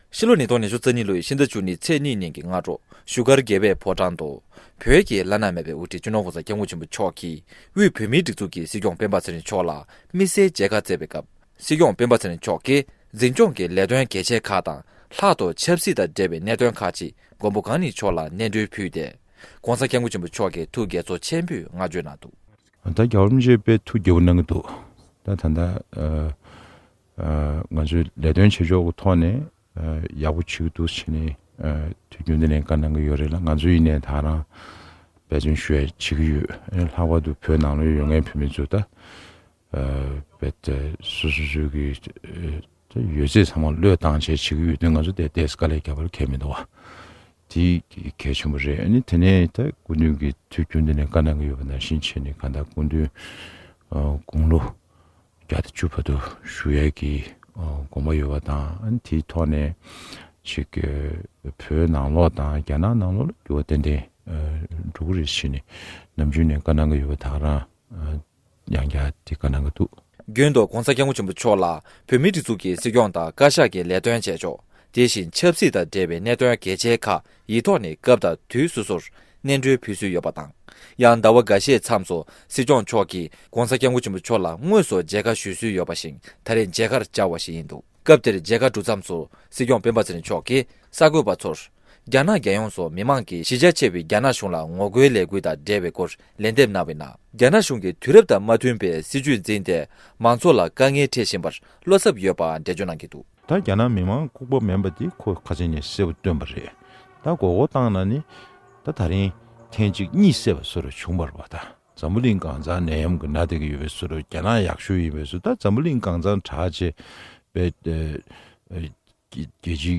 མཇལ་ཁ་གྲུབ་མཚམས་སུ་སྲིད་སྐྱིང་སྤེན་པ་ཚེ་རིང་མཆོག་གིས་གསར་འགོད་པ་ཚོར། རྒྱ་ནག་རྒྱལ་ཡོངས་སུ་མི་མང་གི་གཞི་རྒྱ་ཆེ་བའི་རྒྱ་གཞུང་ལ་ངོ་རྒོལ་ལས་འགུལ་དང་འབྲེལ་བའི་སྐོར་ལན་འདེབས་གནང་བའི་ནང་། རྒྱ་ནག་གཞུང་གིས་དུས་རབས་དང་མ་མཐུན་པའི་སྲིད་བྱུས་འཛིན་ཏེ་མང་ཚོགས་ལ་དཀའ་ངལ་སྤྲད་བཞིན་པར་བློ་འཚབ་ཡོད་པ་འགྲེལ་བརྗོད་གནང་གི་འདུག